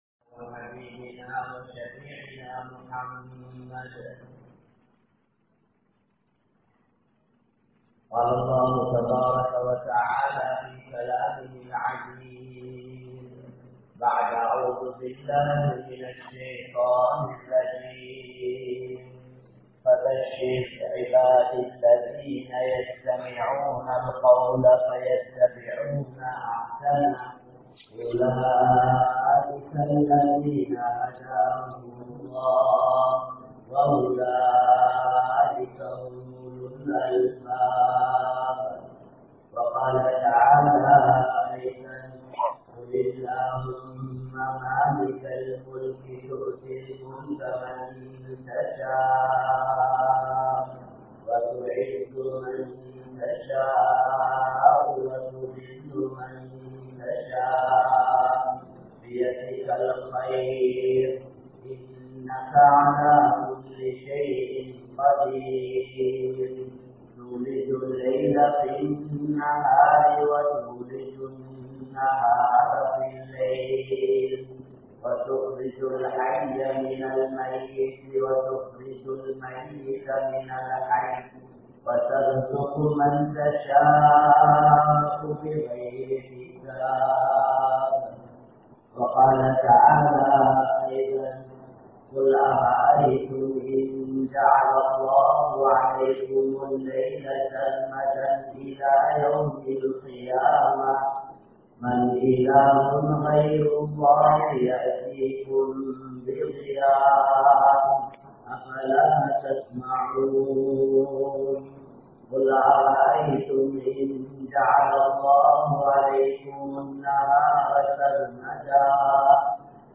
Allah′vai Maranthu Vidaatheerhal (அல்லாஹ்வை மறந்து விடாதீர்கள்) | Audio Bayans | All Ceylon Muslim Youth Community | Addalaichenai
South Eastern University Jumua Masjith